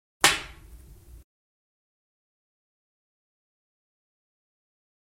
На этой странице собраны звуки бильярда: от четких ударов кием до глухого стука шаров и их падения в лузу.
Звук битка поставили на стол белый шар